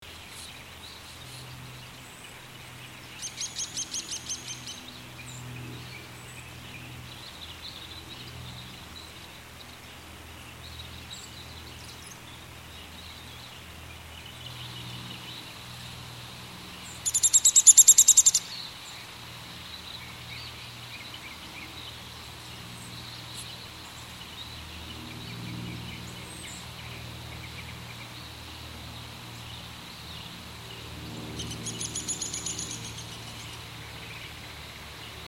Buntspecht Ruf
Buntspecht-Ruf-Voegel-in-Europa.mp3